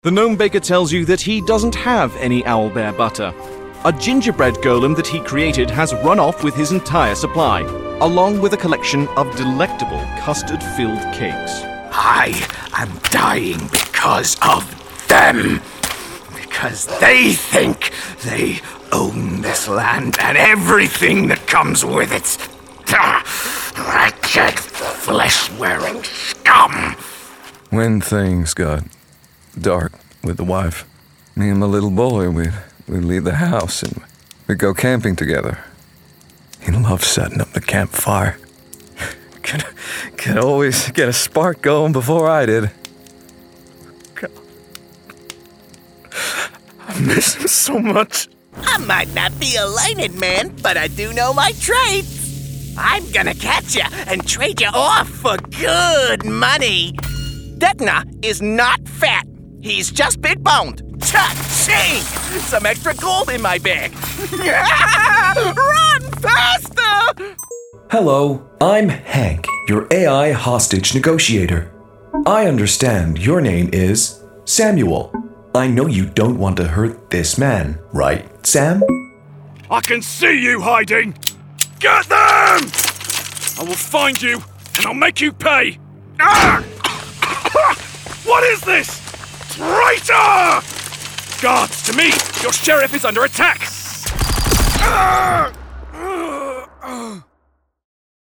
Gaming Showreel
Male
Welsh
Confident
Friendly